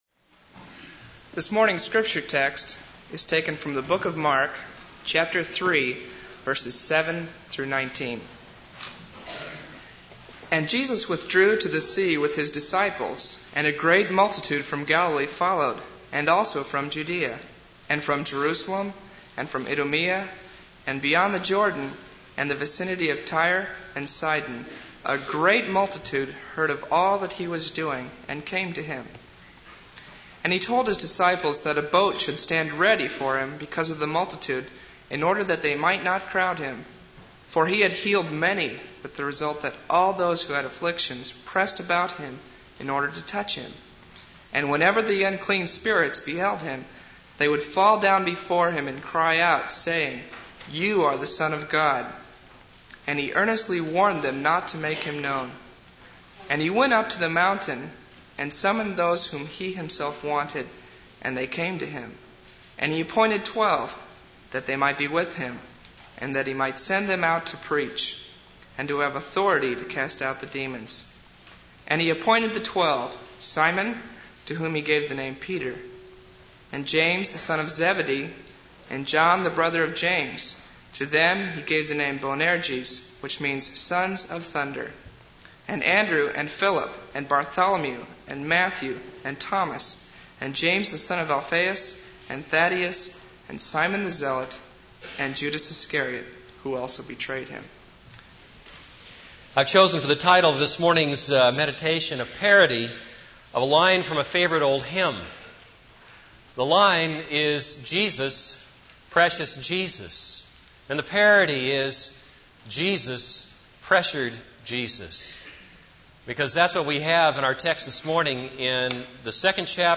This is a sermon on Mark 3:7-19.